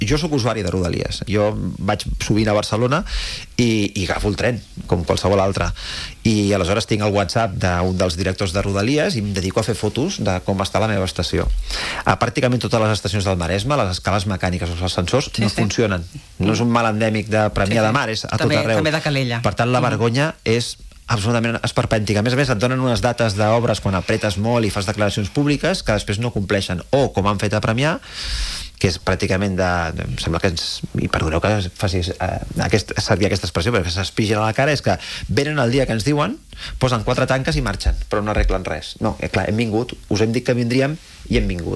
El nou president del Consell Comarcal del Maresme, Rafa Navarro (JUNTS), ha passat pels estudis de Ràdio TV per analitzar els principals reptes de la comarca.